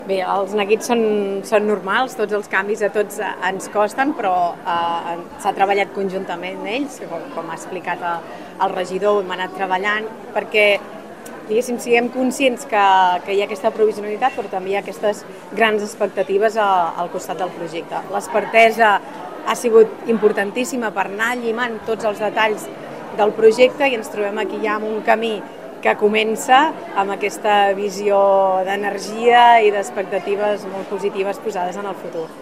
Des de l’Ajuntament entenen aquest neguit per part dels paradistes, però són optimistes de cara a oferir un nou mercat molt potent i que el consolidi com a espai de referència a la comarca. Són declaracions de l’alcaldessa de Palamós, Maria Puig.